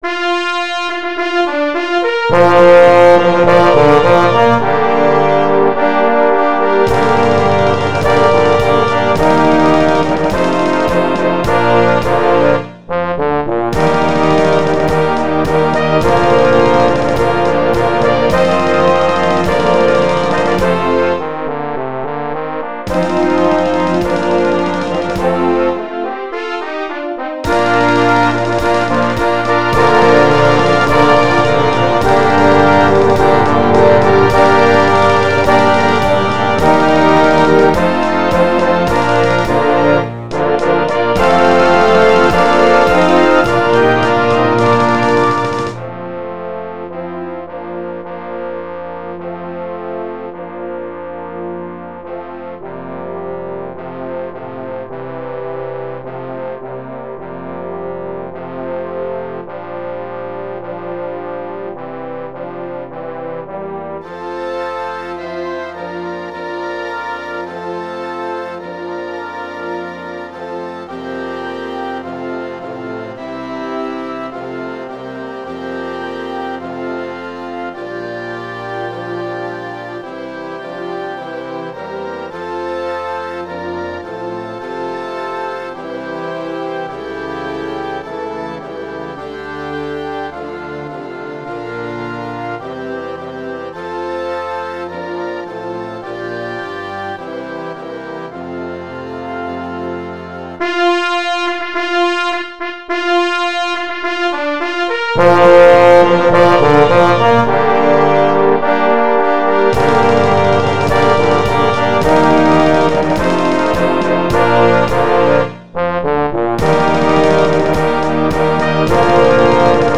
Blasmusik & Orchester